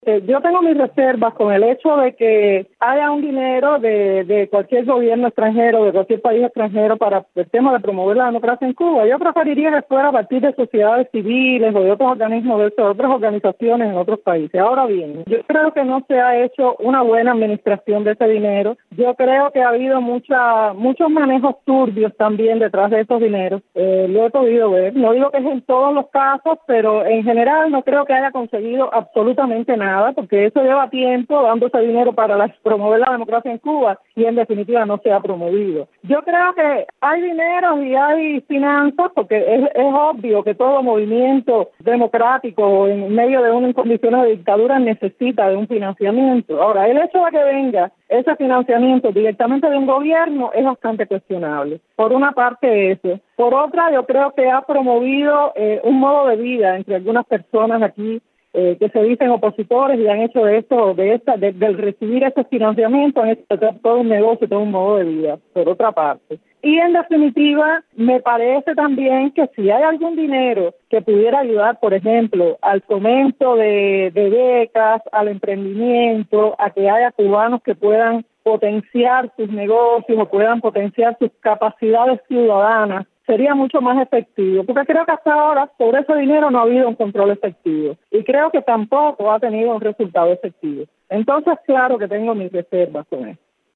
bloguera cubana independiente